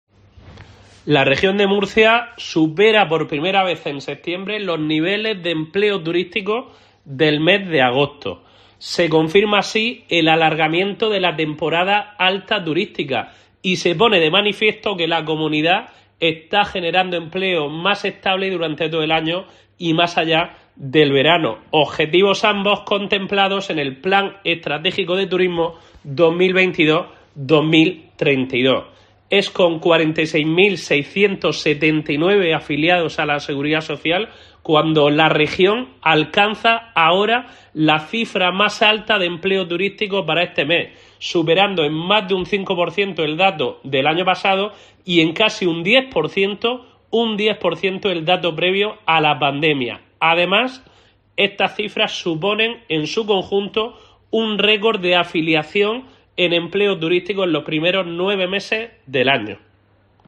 Juan Francisco Martínez, director del ITREM